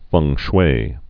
(fŭng shwā)